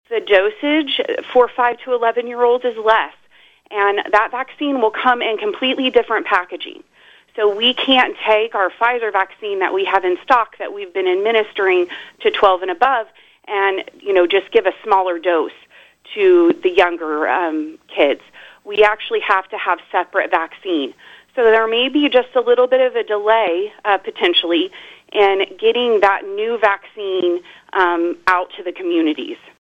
Having said that on KVOE’s Morning Show recently, she also says the process for getting shots in arms will have some logistical differences from the process used for teenagers and adults.